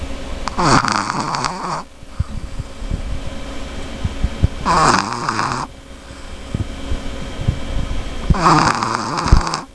hear the puppy snore?
snore.wav